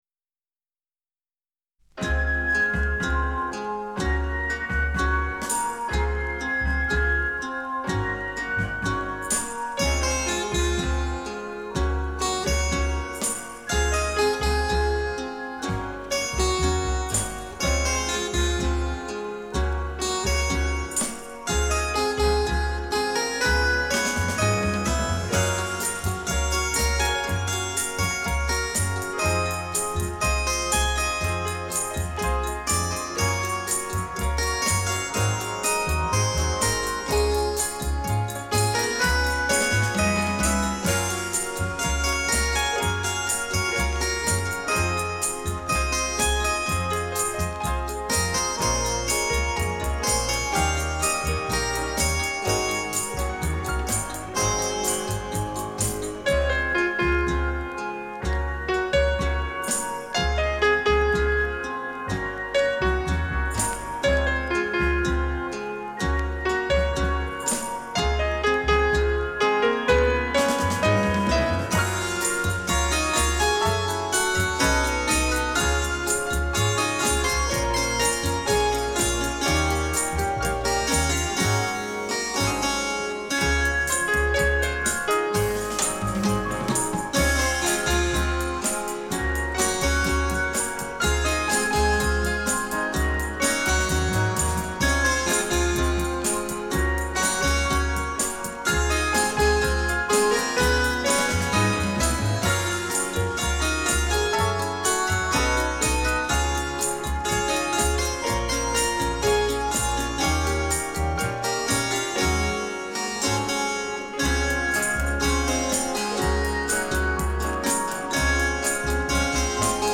Запись, которая поднимает настроение.